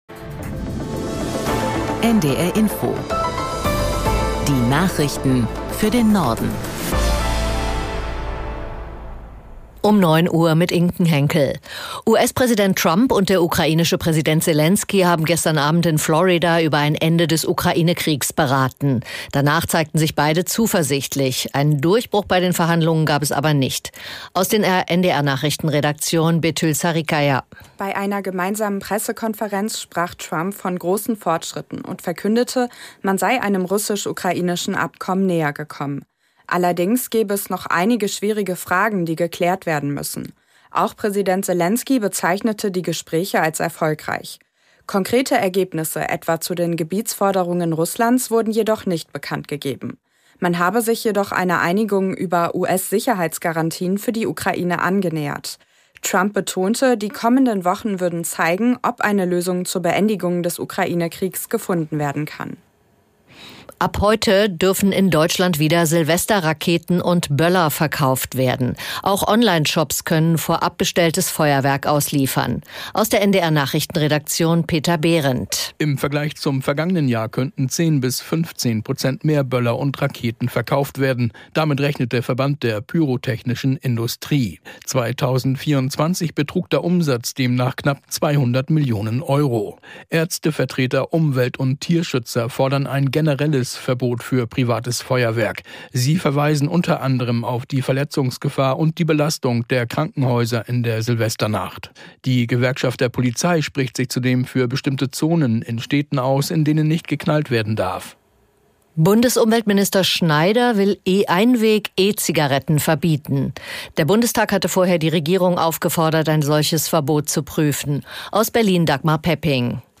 Die aktuellen Meldungen aus der NDR Info Nachrichtenredaktion.